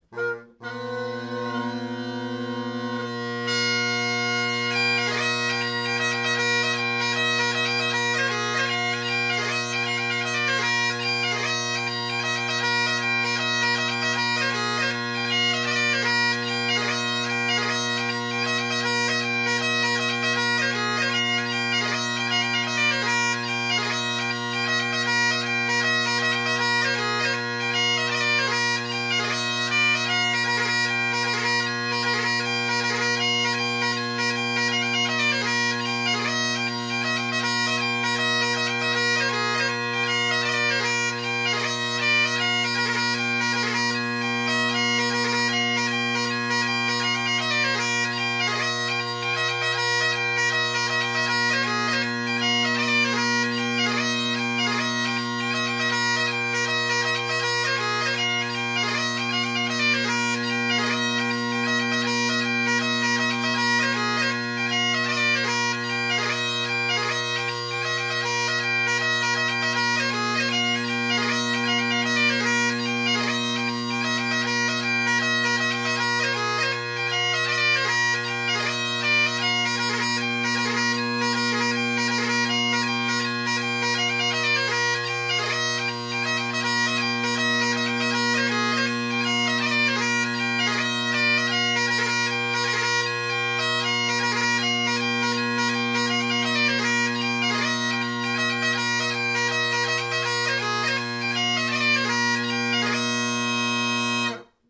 Bagpipe
A two part hornpipe by William MacDonald, “The Champion of the Seas”.